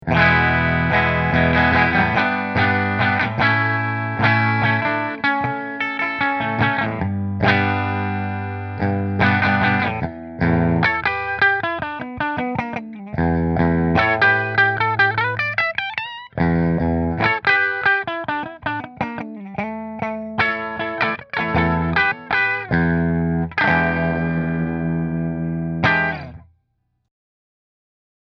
• 2 Music Man Custom Pickups
Music Man JP-6 Mystic Dream Bridge Through Fender